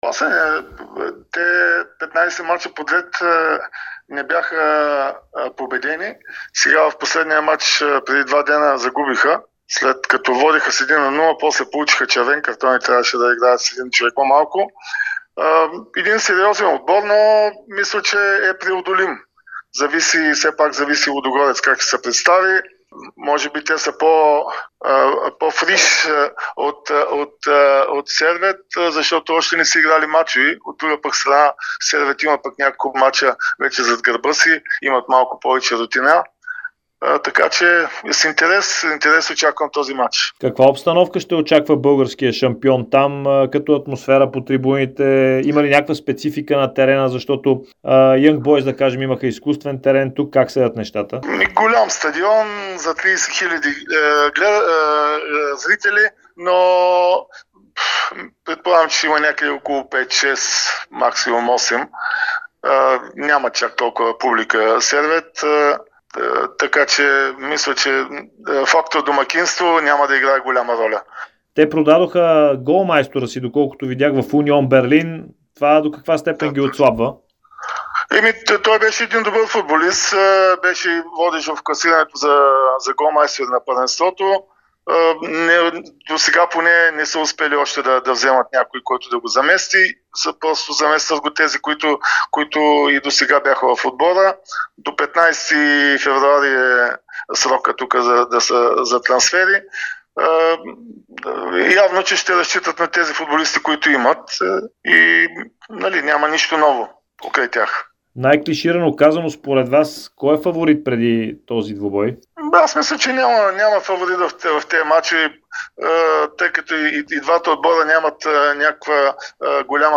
Бившият национал Петър Александров говори пред Дарик и dsport преди двубоя от плейофите на Лигата на конференциите между Сервет и Лудогорец. Александров живее от дълго време в Швейцария и познава отлично местния футбол.